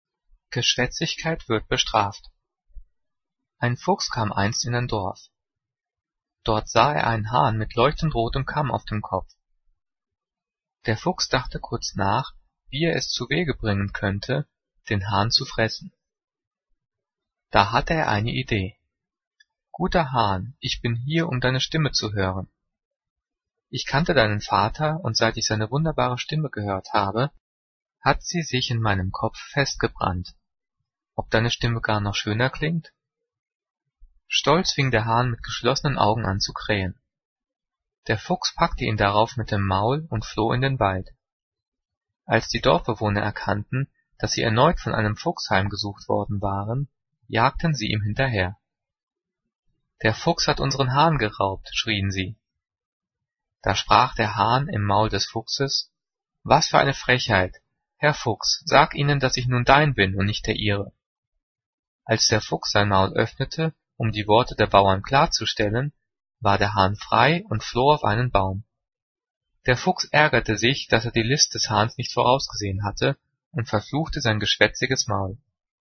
Gelesen: